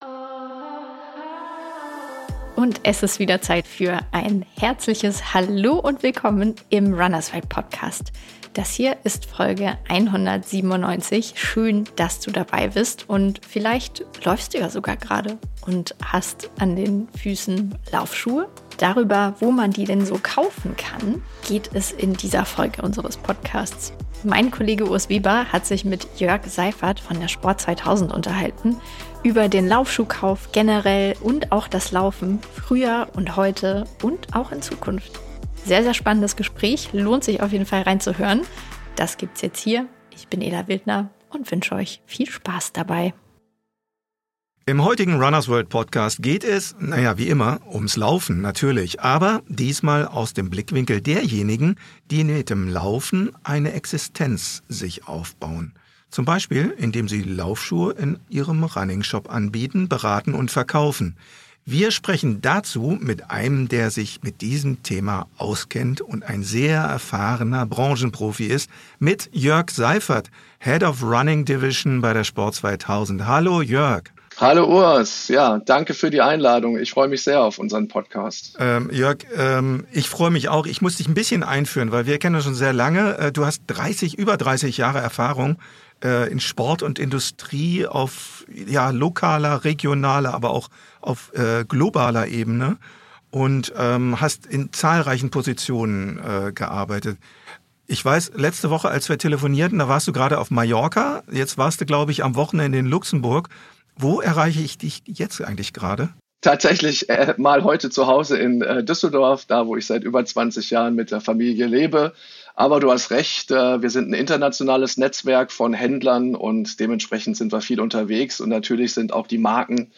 Gesprochen haben die beiden unter anderem darüber, wie es einen in den Laufschuhhandel verschlägt, wie dieser eigentlich funktioniert und was sich im Verlauf der Jahre geändert hat. Wir wünschen viel Spaß mit dem interessanten Gespräch, das viele spannende Insights beleuchtet.